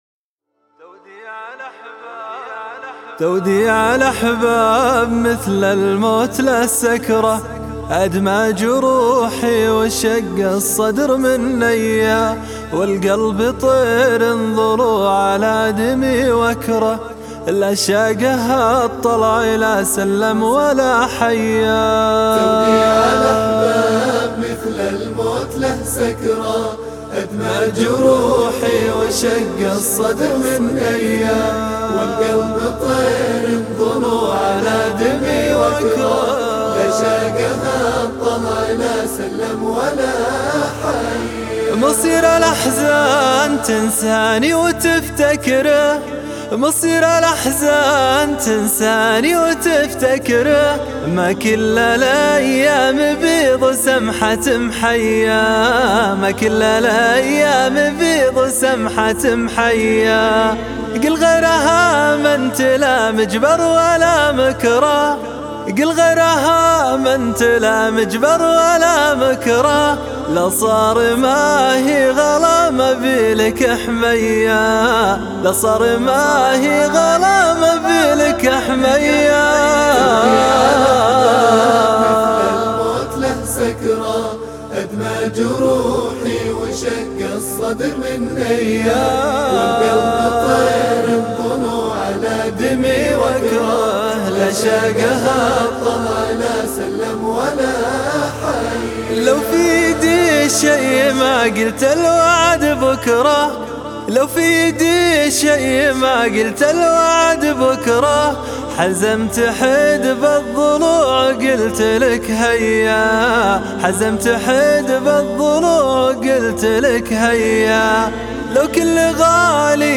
الشيله